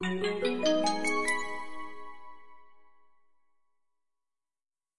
游戏音效 " FX201
描述：爆炸哔哔踢游戏gameound点击levelUp冒险哔哔sfx应用程序启动点击
Tag: 爆炸 单击 冒险 游戏 应用程序 点击的LevelUp 启动 gamesound 哔哔声 SFX